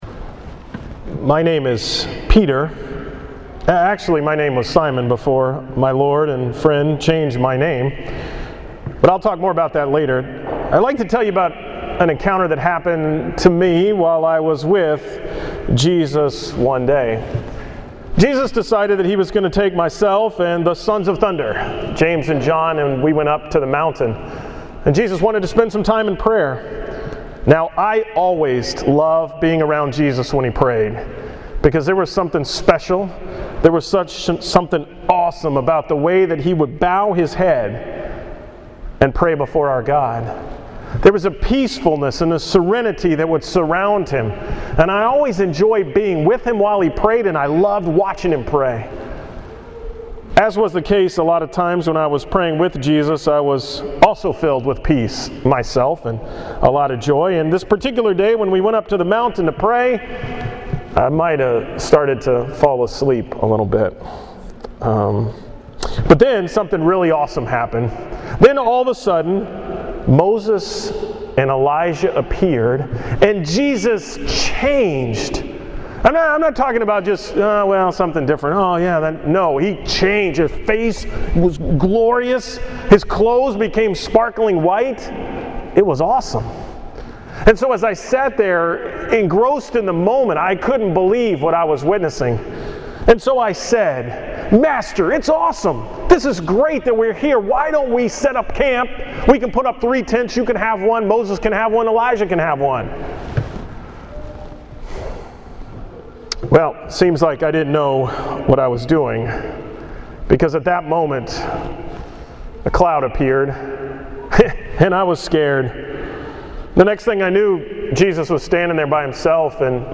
From the 9 am Mass on February 24th 2nd Sunday of Lent